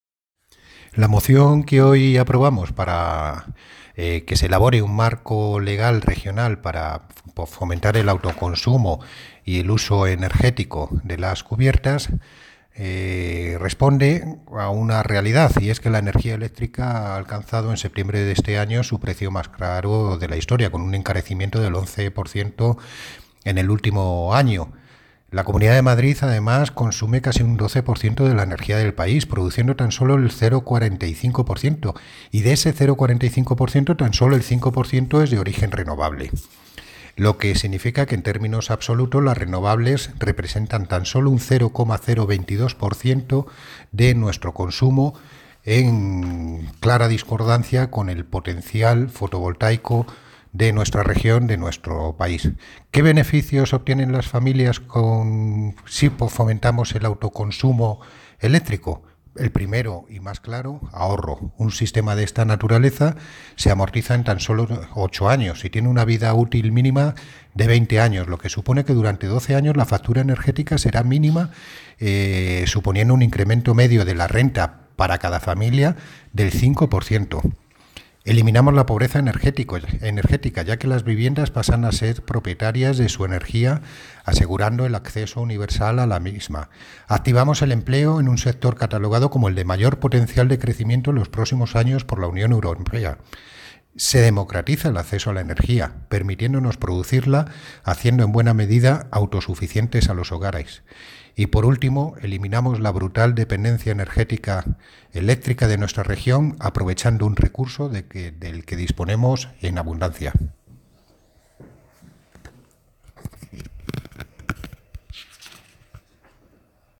Audio - Miguel Ángel Ortega (Concejal de Medio Ambiente, Parques y Jardines y Limpieza Viaria)